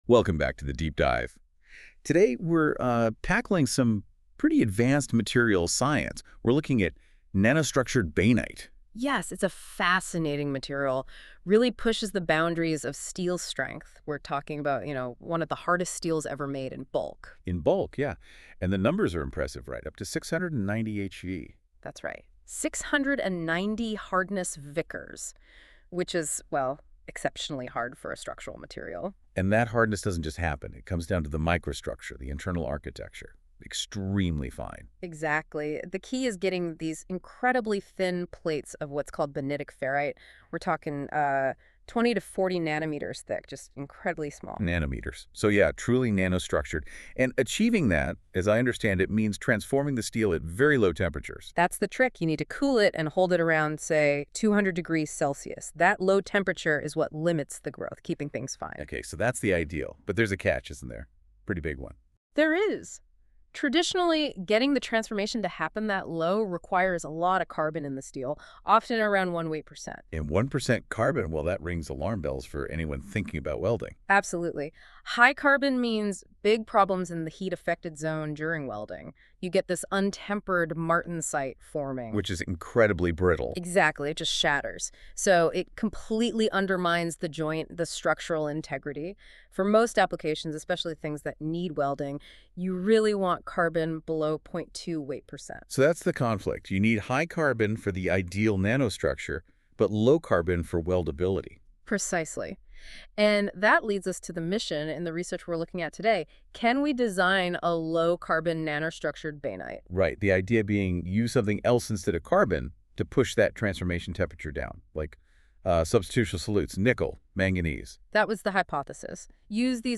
Podcasts A conversation about this work.